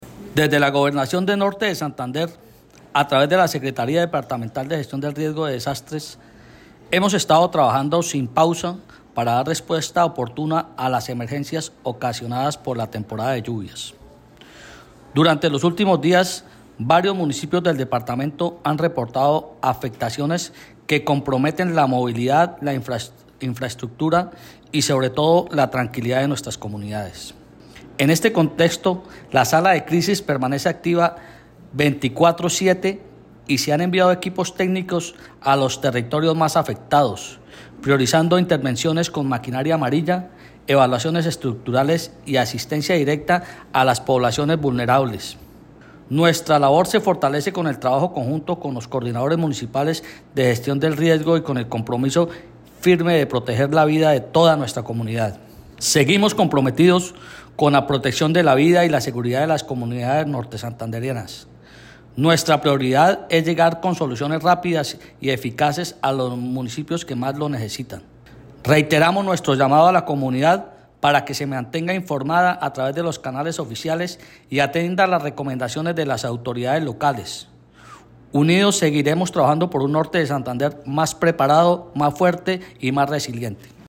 Audio-de-William-Vera-secretario-de-Riesgos.mp3